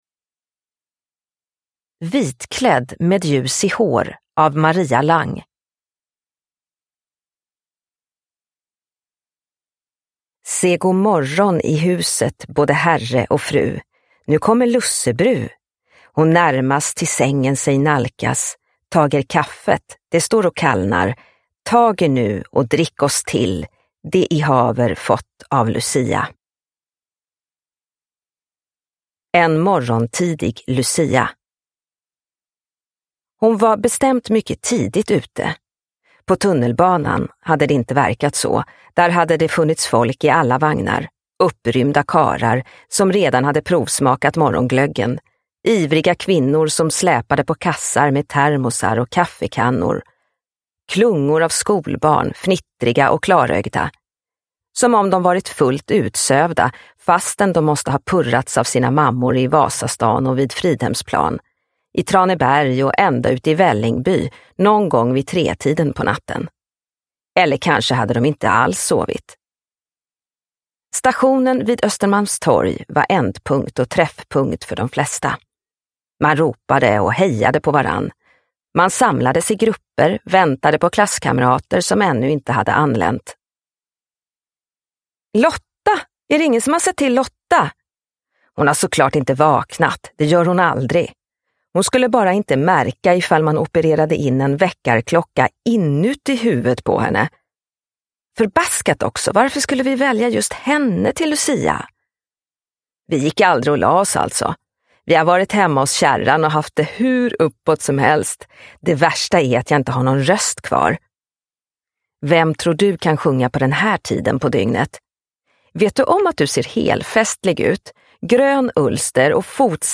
Vitklädd med ljus i hår – Ljudbok – Laddas ner